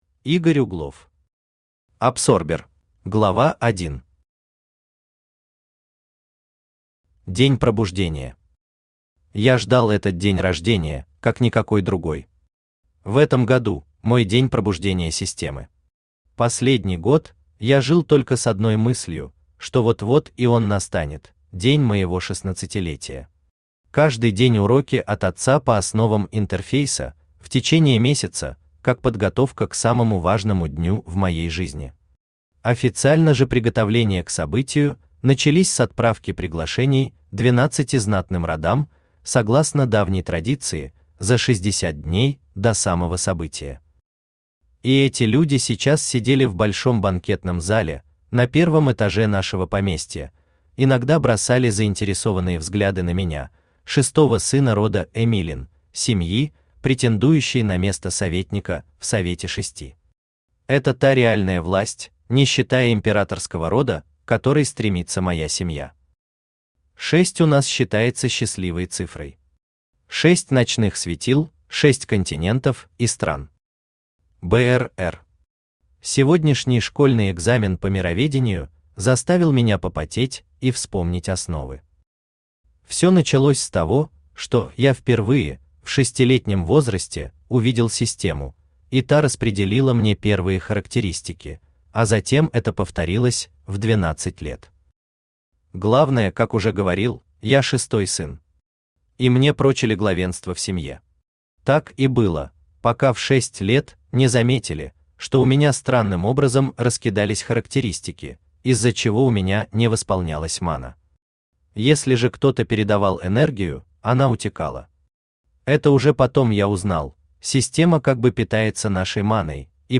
Аудиокнига Абсорбер | Библиотека аудиокниг